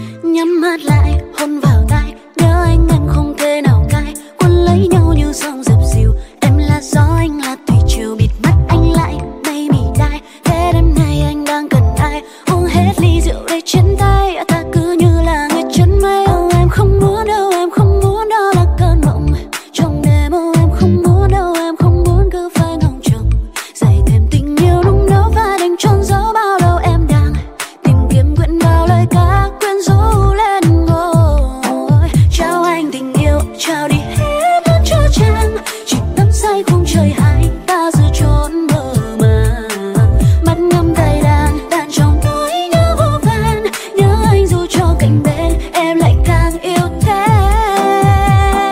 Nhạc Trẻ.